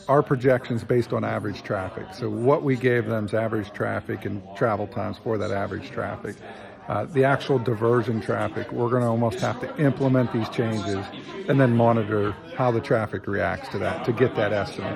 At their meeting Tuesday evening, Indiana Borough Council presented the results of a pedestrian safety study conducted by PENNDOT.